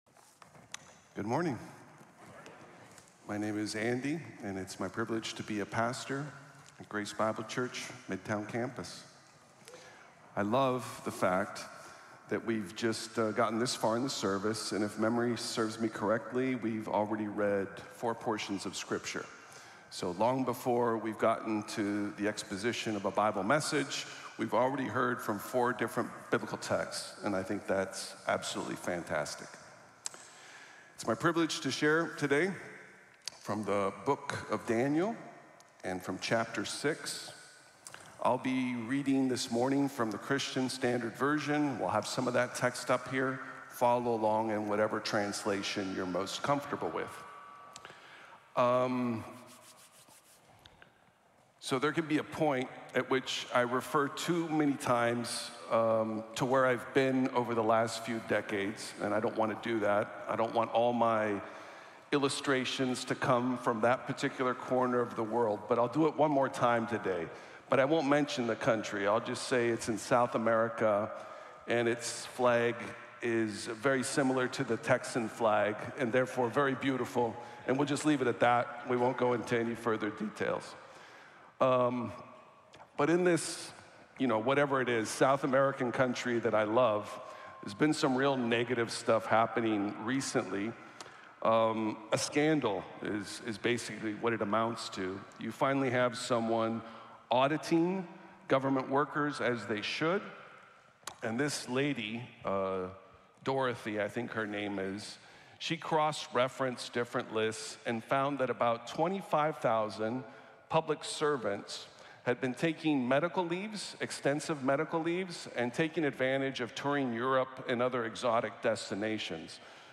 El Dios vivo cumple | Sermón | Iglesia Bíblica de la Gracia